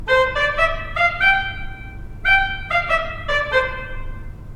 C’est un instrument assez original tant par sa conception que par sa sonorité.
Le Clairon 4 d’Hauptwerk (St Anne, Moseley) semble assez dans la même veine que la régale bien que différent.
D’un point de vu acoustique, les instruments à anche et perce cylindrique (j’exclue les saxophones), se caractérisent par l’absence d’harmonique de rang pair... cela fait un timbre particulier.
clairon4.mp3